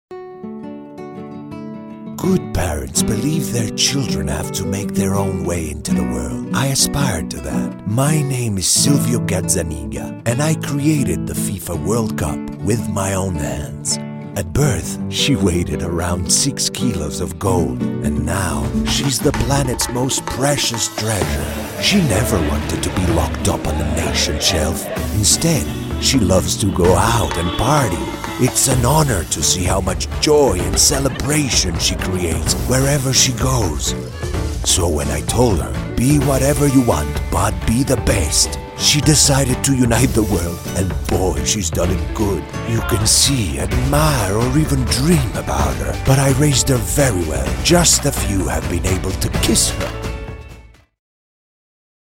Male
Authoritative, Character, Conversational, Deep, Gravitas, Versatile, Warm